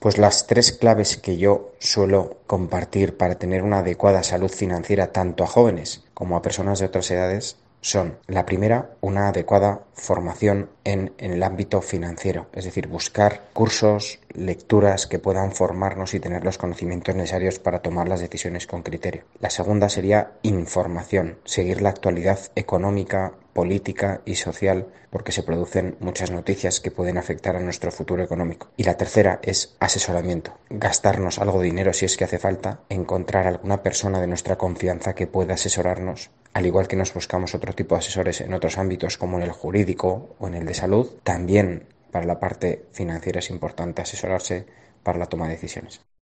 experto financiero, nos explica qué claves aplicar para tener buena salud financiera